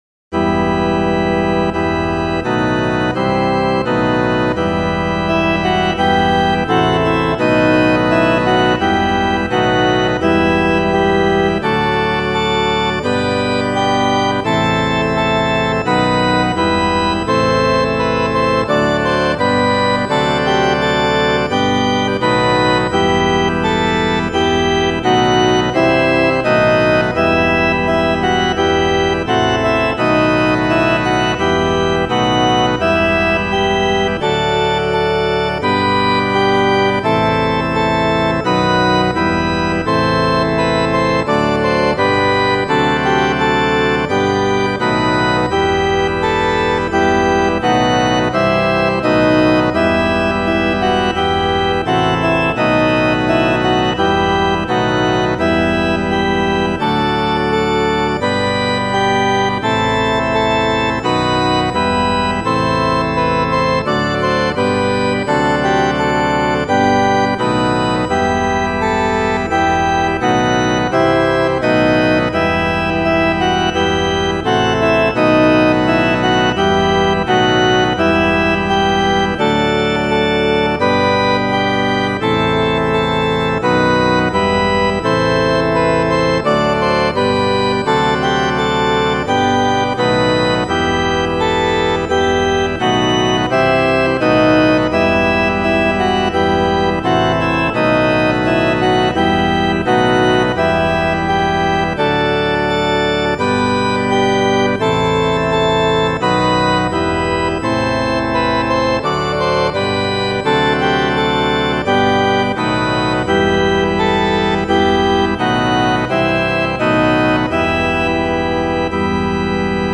A simple fake organ backing to learn the tune: